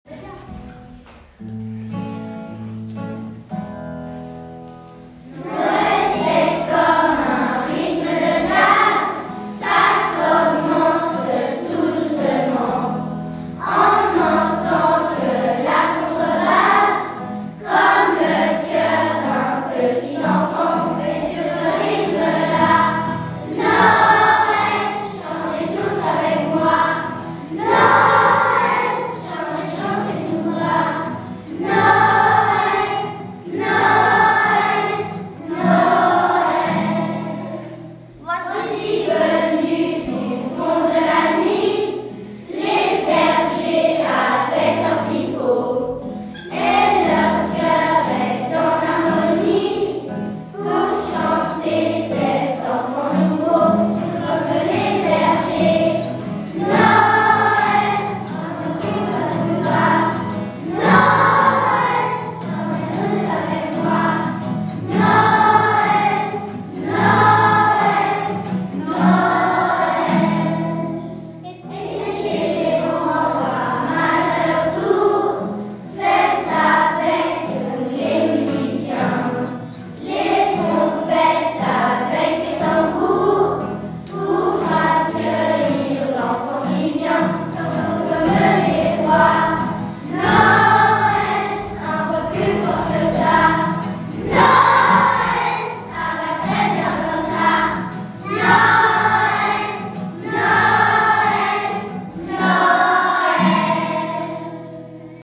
2021-22 : “Chantée de Noël”, les classes de Corcelles
Groupe 1 : classes 1-2P44, 5P42 et 6P41